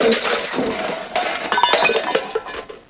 00410_Sound_crash1